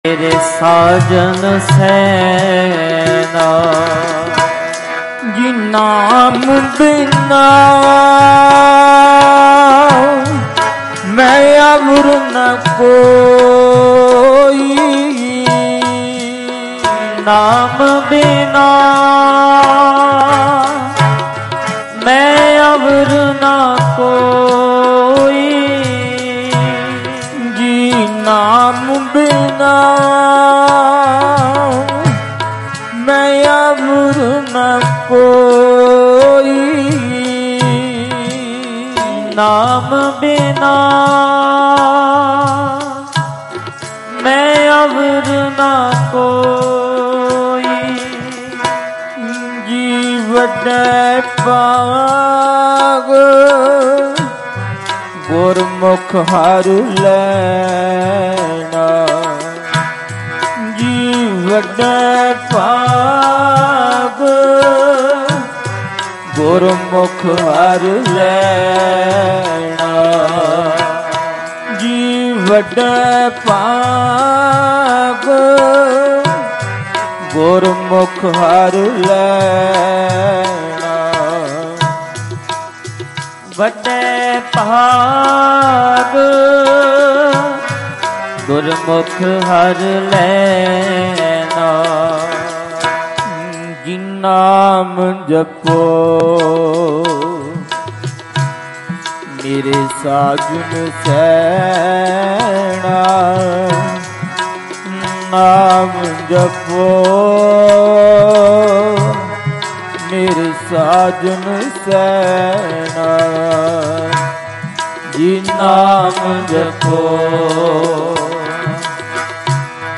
Live Shukrana Samagam Dhadrianwale From Parmeshar Dwar 20 April 2025 Dhadrianwale
Mp3 Diwan Audio by Bhai Ranjit Singh Ji Dhadrianwale at Parmeshardwar